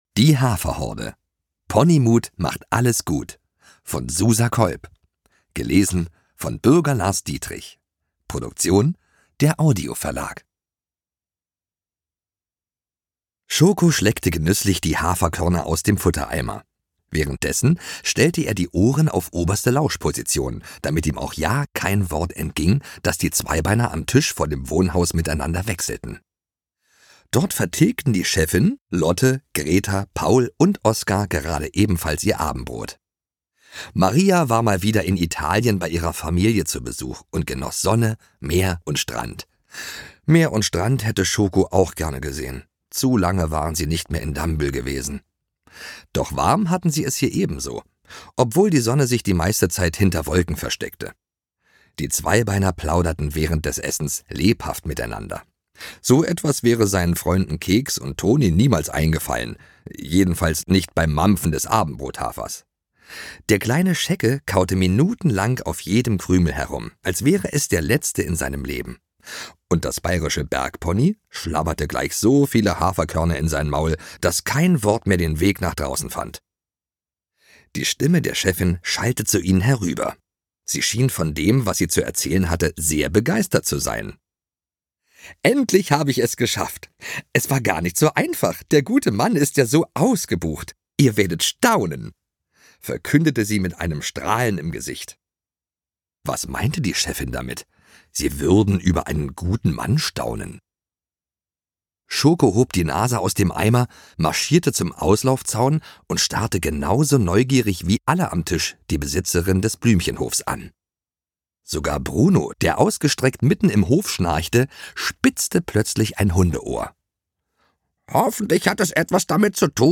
Ungekürzte Lesung mit Bürger Lars Dietrich (2 CDs)
Bürger Lars Dietrich (Sprecher)